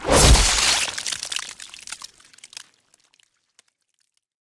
largeblade.wav